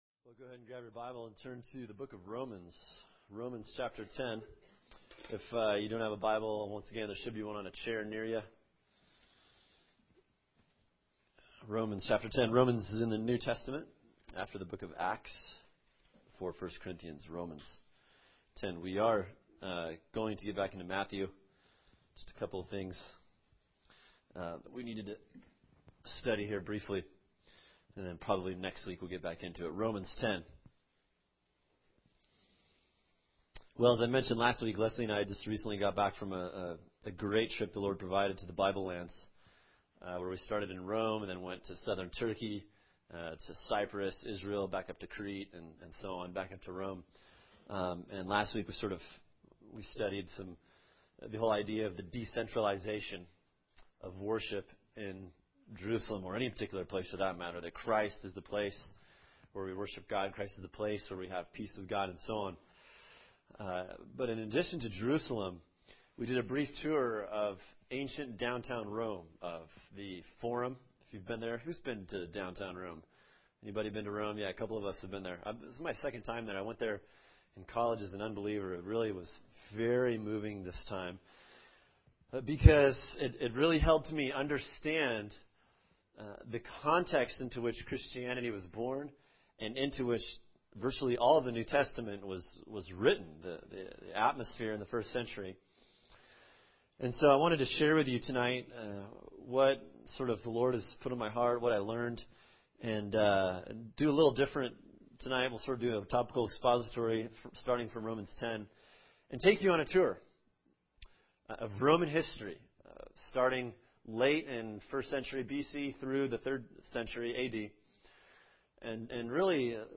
[sermon] Romans 10:9 “Confessing Jesus As Lord” | Cornerstone Church - Jackson Hole